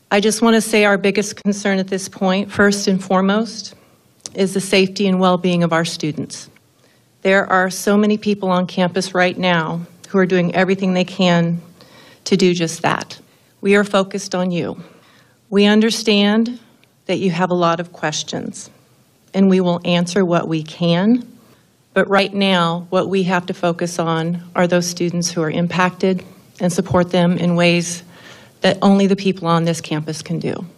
During a press briefing UW-Platteville Chancellor Tammy Evetovich said,